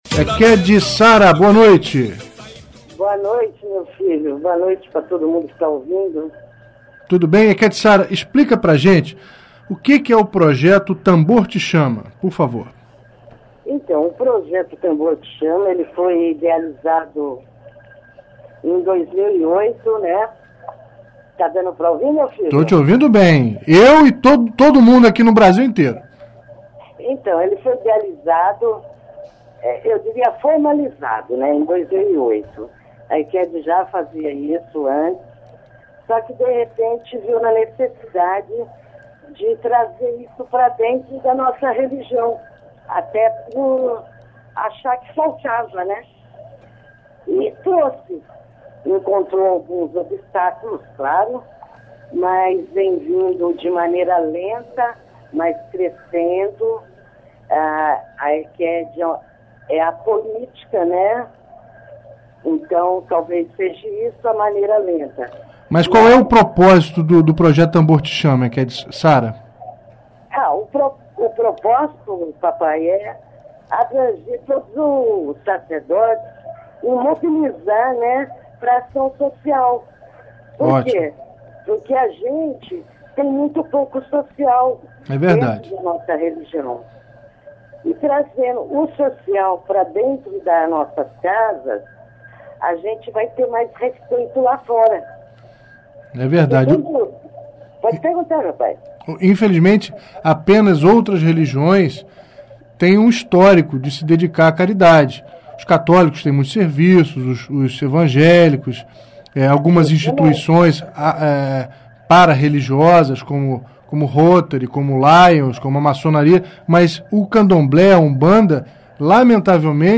Entrevistas e Debates